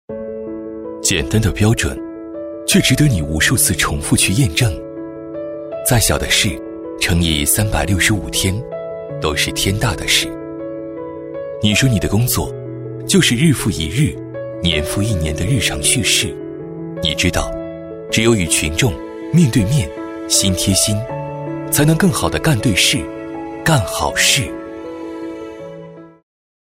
样音试听 - 红樱桃配音-真咖配音-500+真人配音老师 | 宣传片汇报纪录动画英文粤语配音首选平台
红樱桃配音，真咖配音官网—专业真人配音服务商！